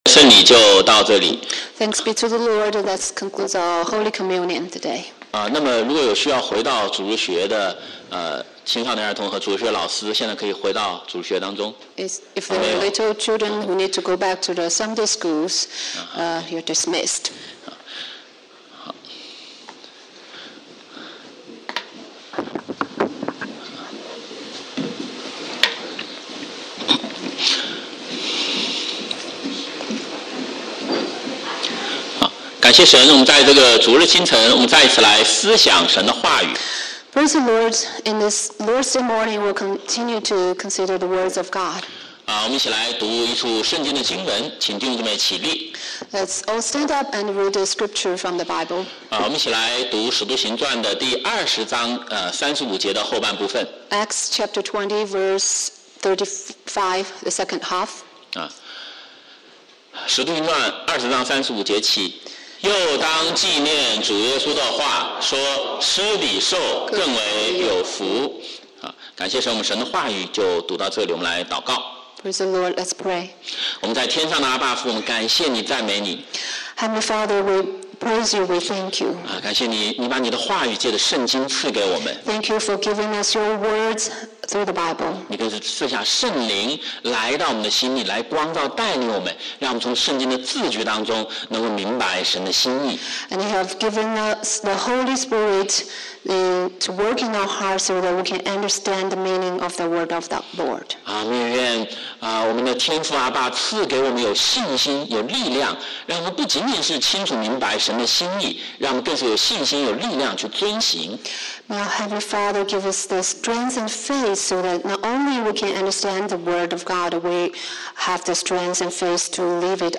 BCCC Sermon